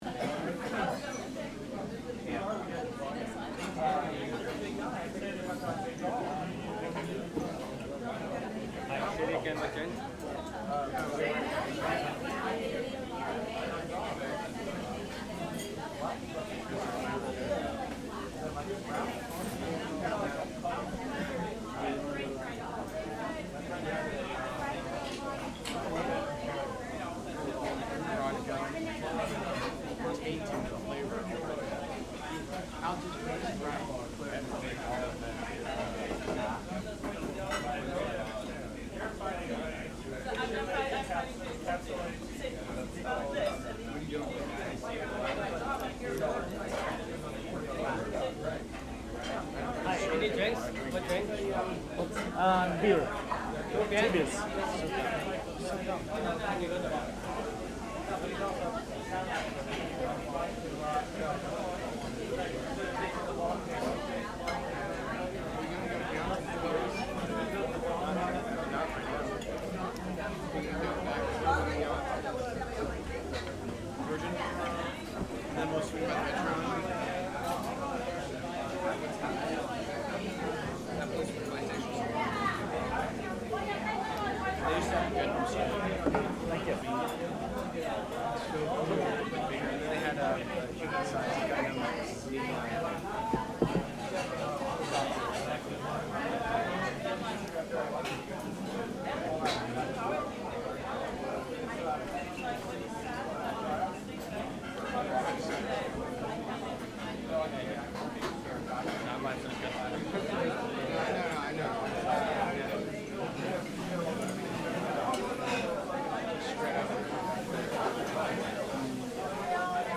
Un fantastico ristorante cinese, realmente al di sopra di qualunque semicinese mai mangiato prima, ci ha accolto, dopo un’attesa in coda al di fuori, per sfamarci con gusti agrodolci e amabilmente fredda birra cinese.
sf_chinatown_restaurant.mp3